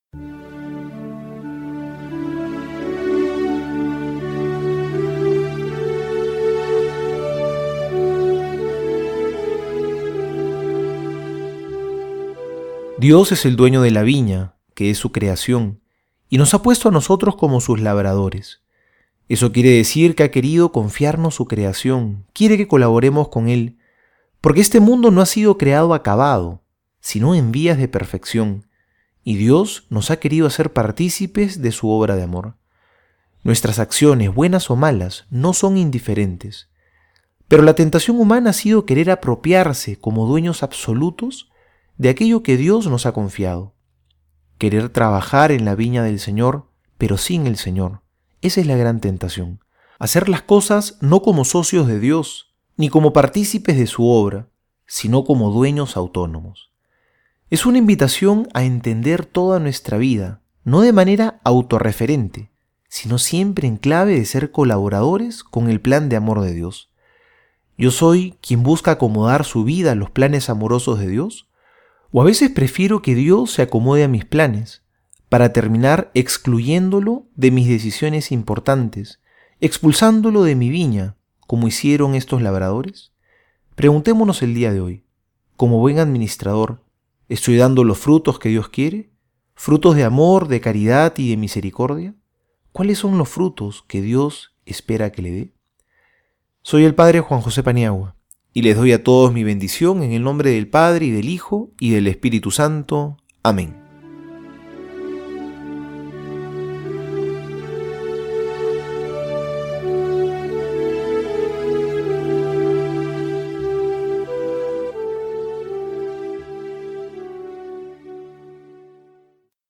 Homilía para hoy:
lunes Marcos 12 1-12homilia.mp3